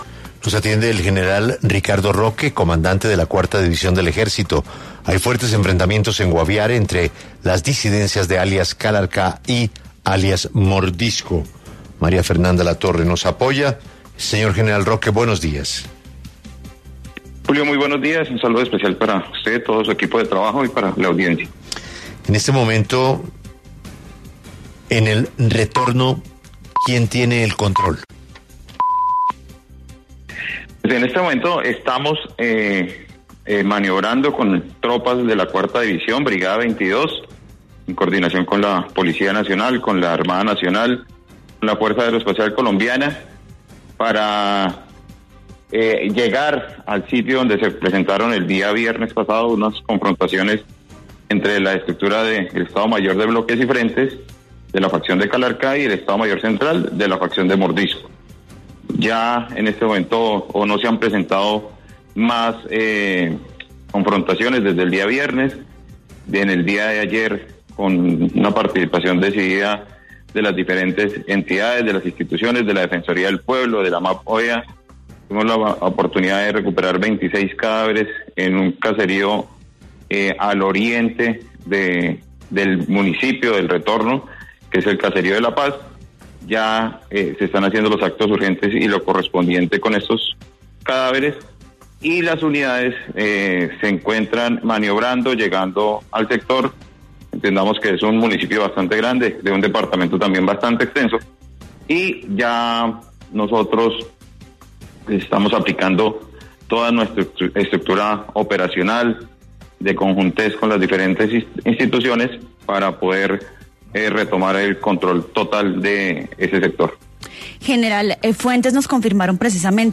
El general Ricardo Roque, comandante de la Cuarta División del Ejército, aseguró en los micrófonos de Caracol Radio que tropas de esa unidad, junto con la Brigada 22, adelantan operaciones para recuperar el control del territorio, en coordinación con la Policía Nacional, la Armada, la Fuerza Aeroespacial Colombiana y organismos de derechos humanos.